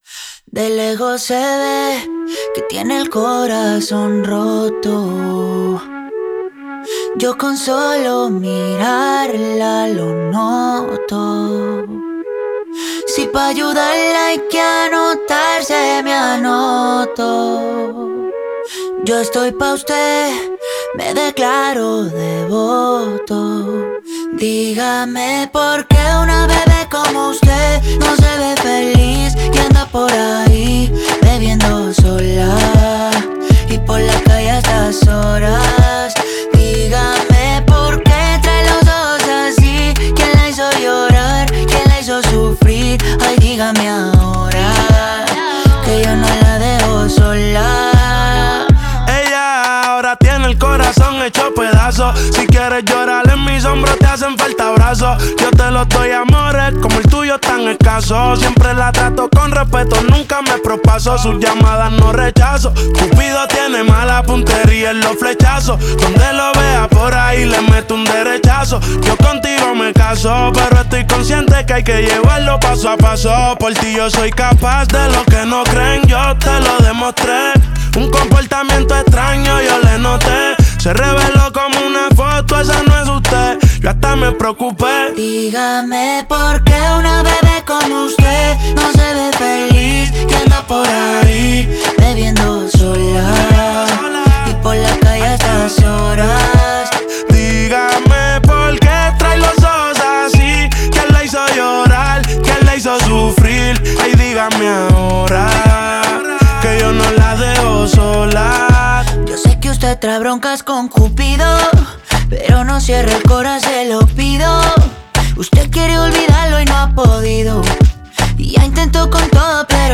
género urbano
romántico tema al ritmo de la fusión Pop-Urbana